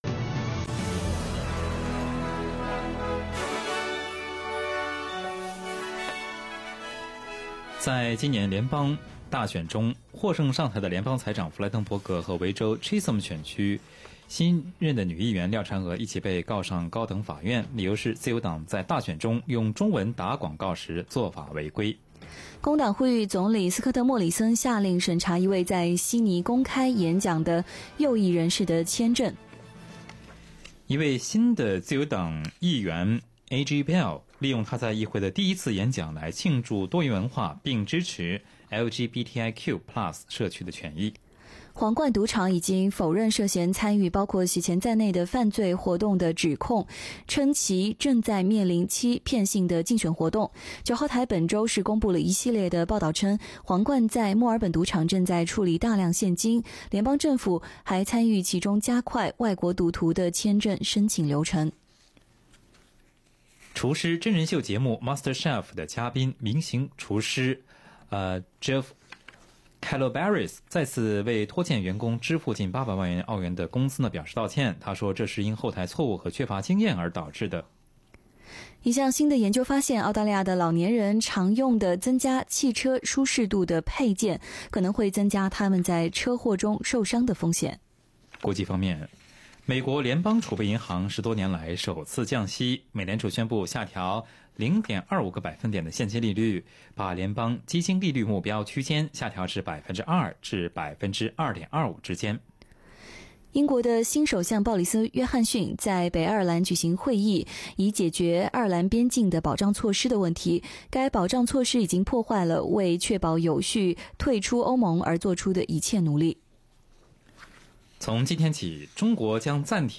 SBS Chinese Morning News August 1